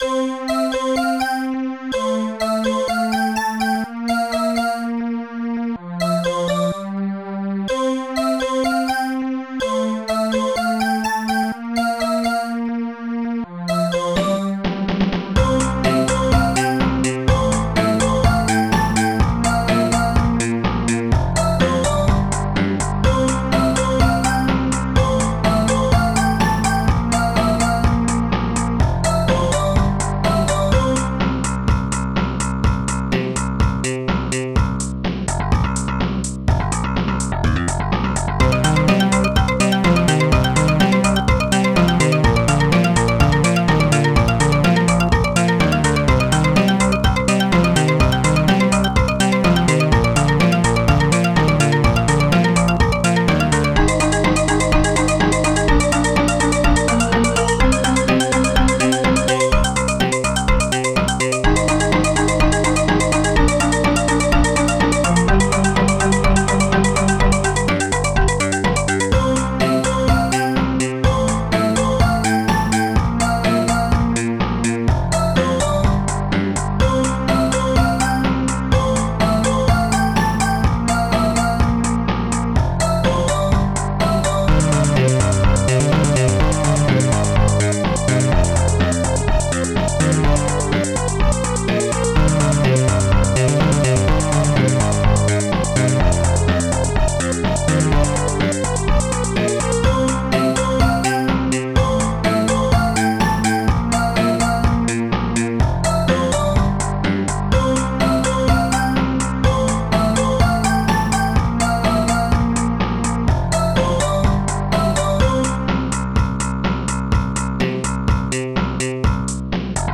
Instruments superbass touch strings2 snare2 hihat2 bassdrum3 polysynth perco